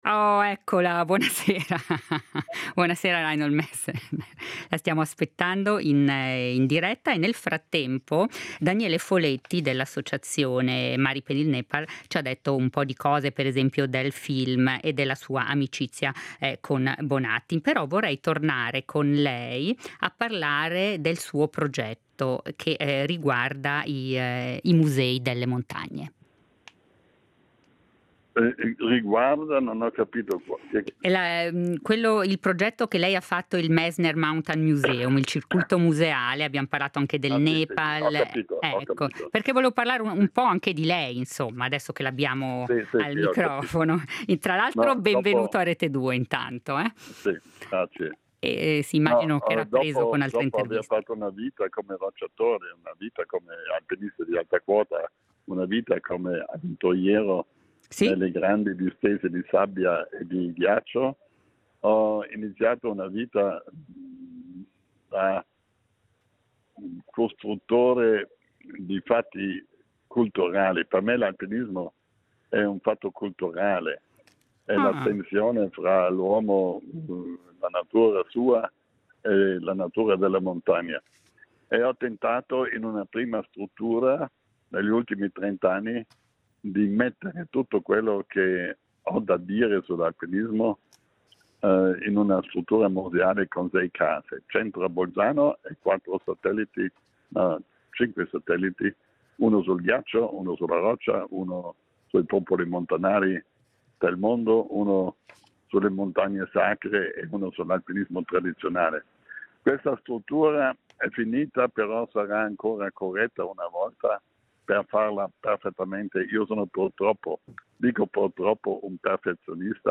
Di alpinismo, di Bonatti e del film ci parla Reinhold Messner di passaggio a Lugano